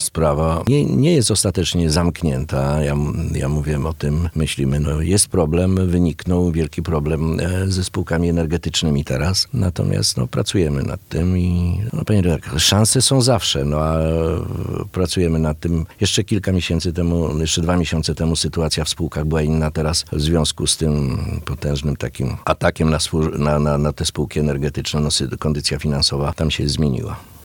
Sytuacja na rynku energetycznym nieco się zmieniła – mówił w tym tygodniu na naszej antenie parlamentarzysta.